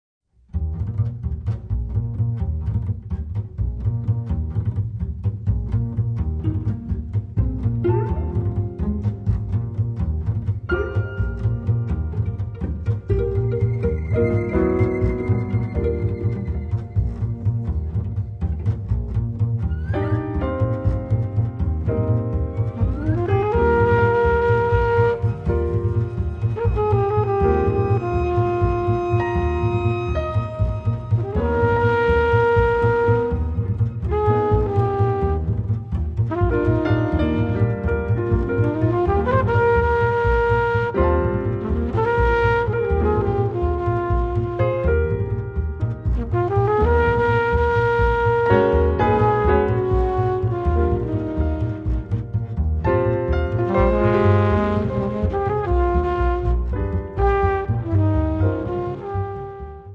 pianoforte
flicorno
contrabbasso
violoncello
nel contrabbasso pulsante e swingante